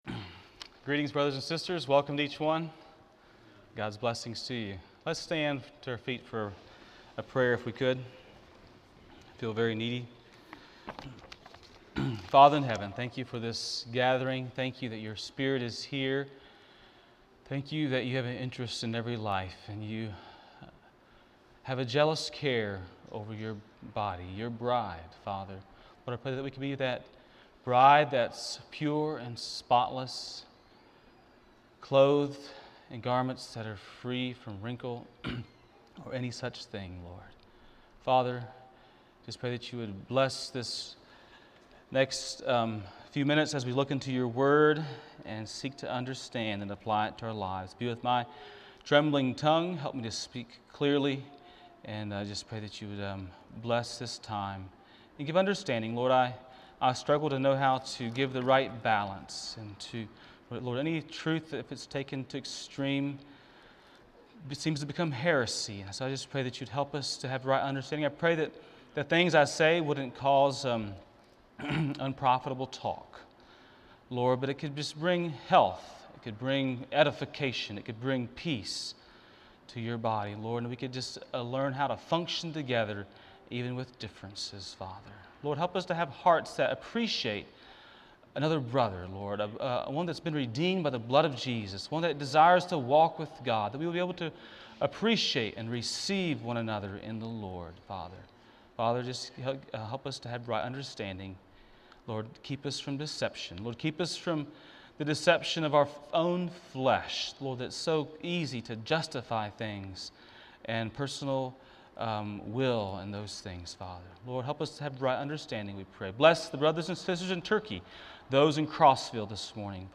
A message from the series "2020 - Messages."